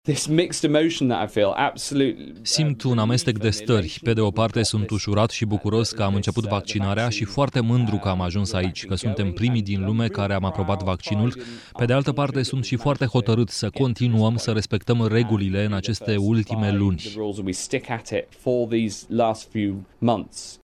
Ministrul britanic al Sănătăţii, Matt Hancock a spus la BBC că în ţară vor ajunge milioane de doze până la finalul anului.
08dec-11-Hancock-amestec-de-lucruri-TRADUS.mp3